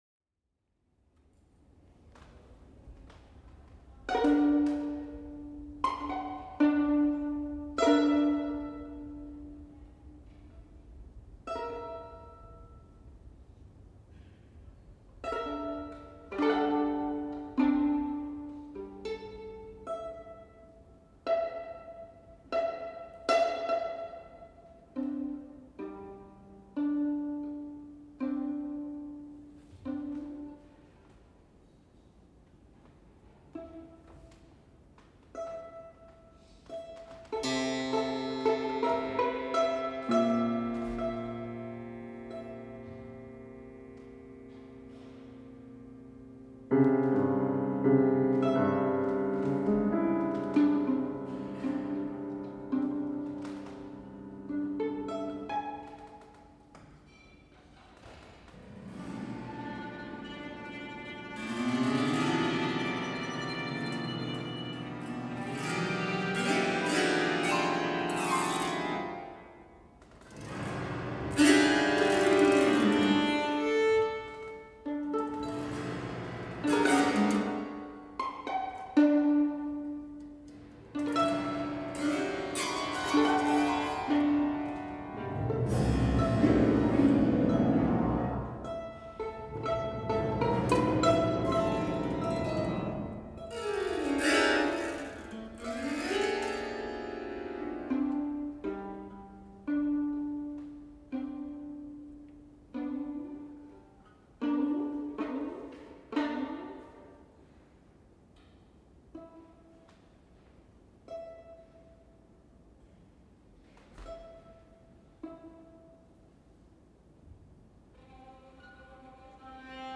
performed live in Paris in December 2021
violin
piano
Five films were screened in the Grand Salon and accompanied by live music.